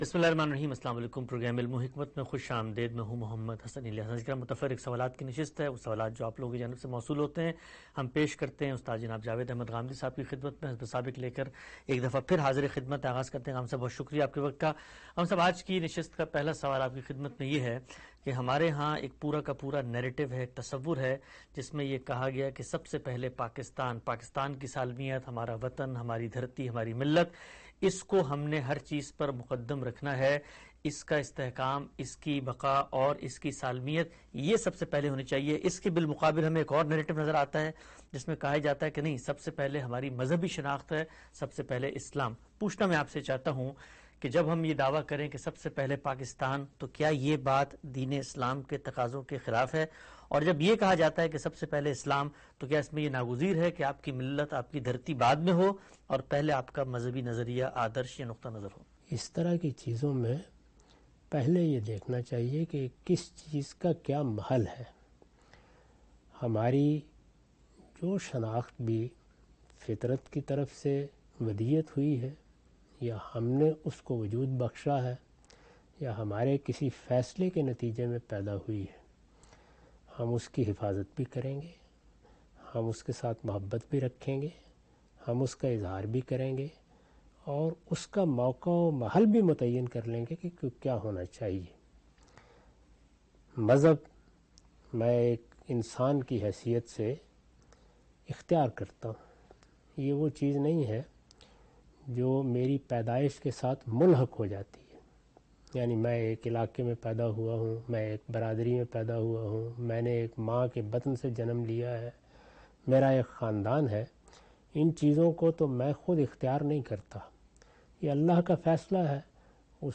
In this program Javed Ahmad Ghamidi answers the questions of different topics in program "Ilm-o-Hikmat".